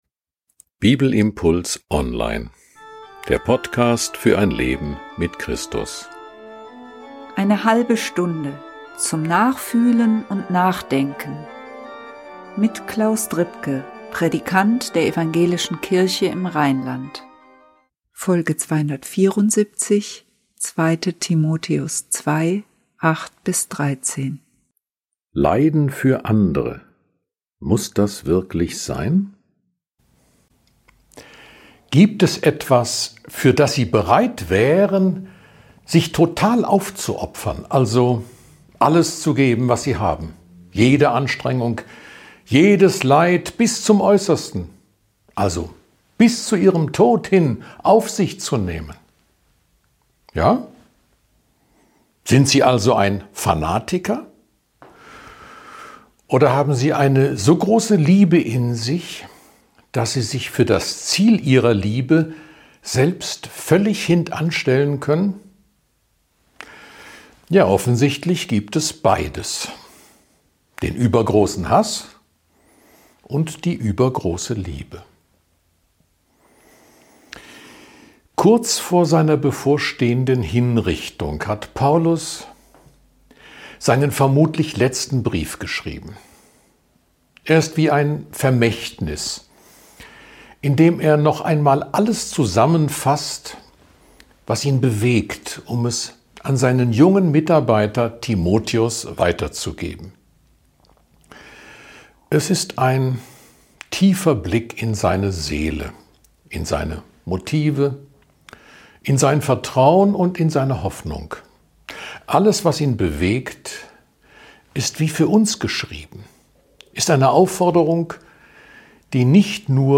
Ein Bibelimpuls zu 2. Timotheus 2, 8-13.